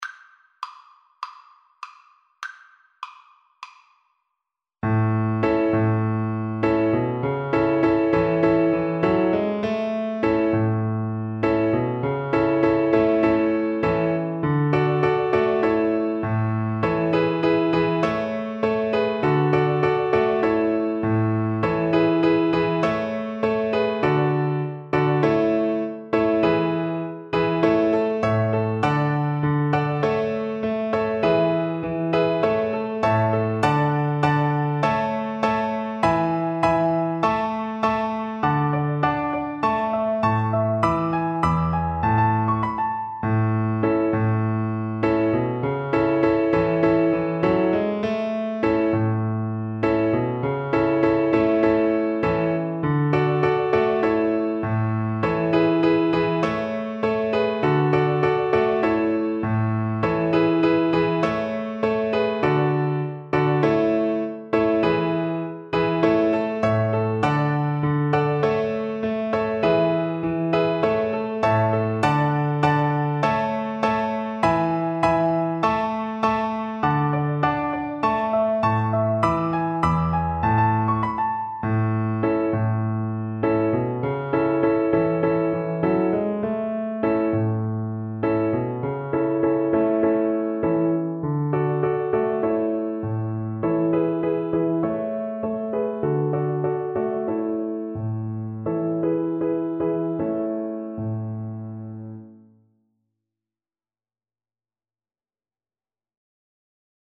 Joyfully =c.100
4/4 (View more 4/4 Music)
A5-F#6
Traditional (View more Traditional Violin Music)
world (View more world Violin Music)